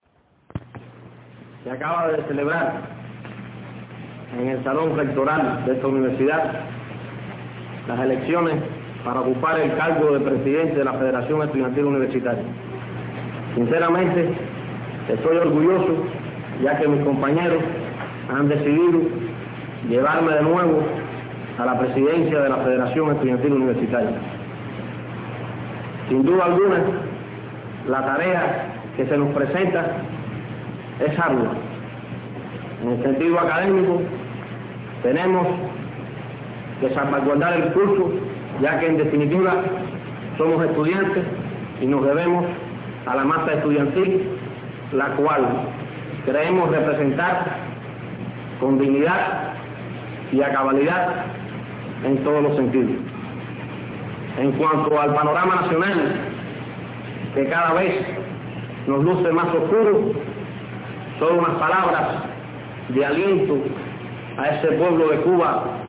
Basta recordar el espíritu de resiliencia y juventud que siempre caracterizó a “Manzanita”, presente en el discurso que diera luego de su elección como presidente de la Federación Estudiantil Universitaria, en septiembre de 1954.